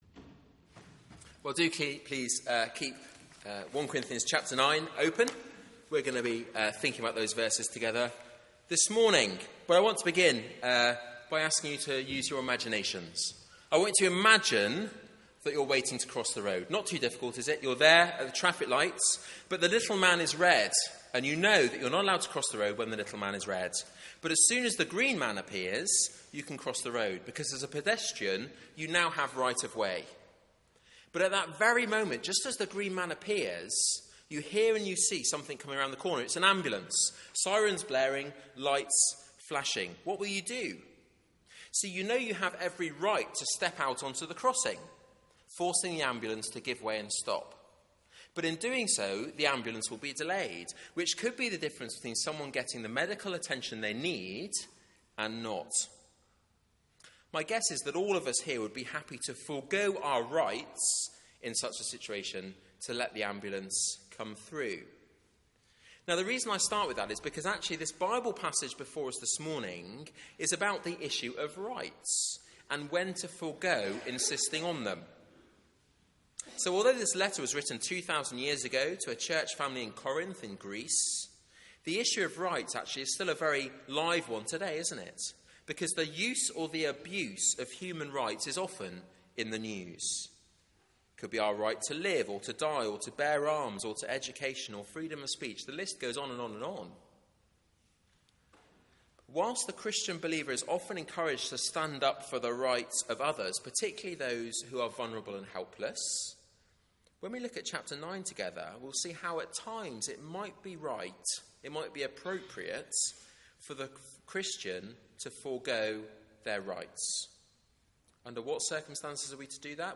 Media for 9:15am Service on Sun 24th May 2015
Theme: Rights can be wrongs Sermon